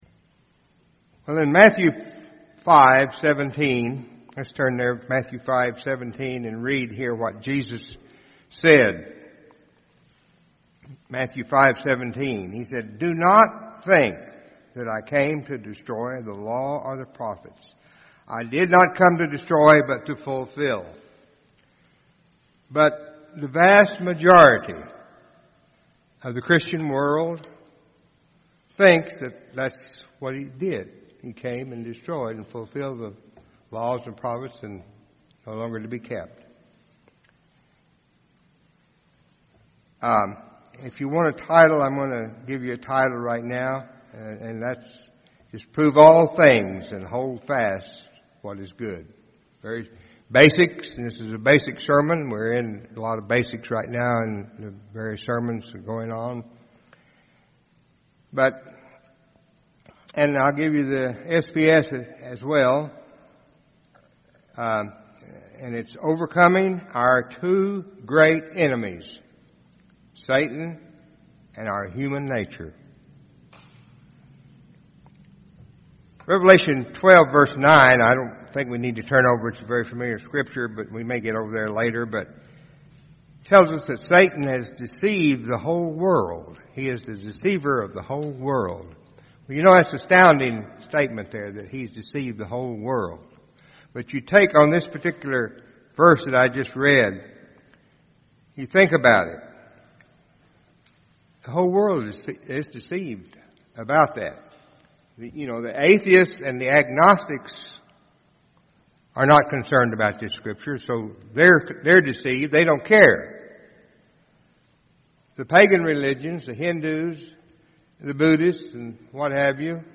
Given in Tulsa, OK
UCG Sermon Studying the bible?